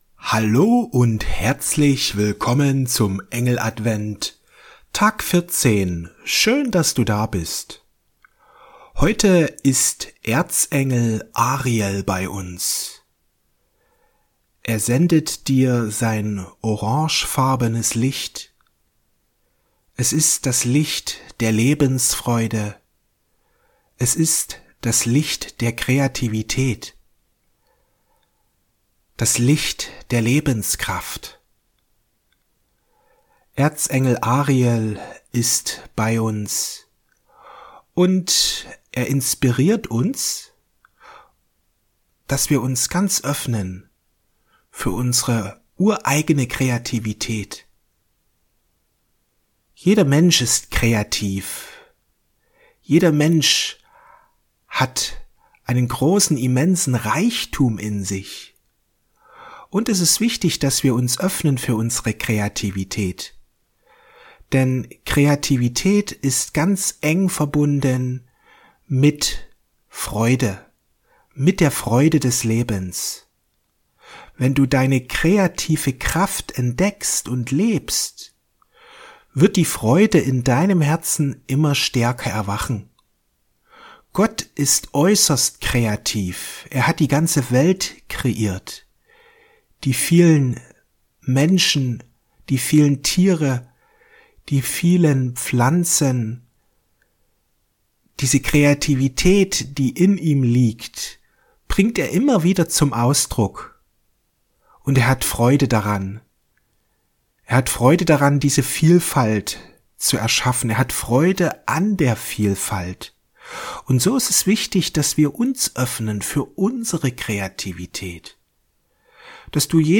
Die Aktivierung und Erhöhung deiner Kreativität Meditation mit Erzengel Ariel